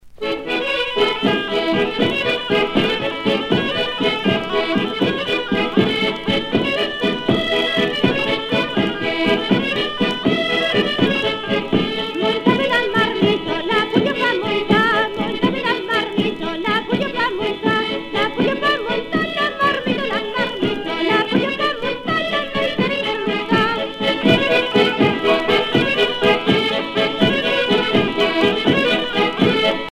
danse : bourree
Pièce musicale éditée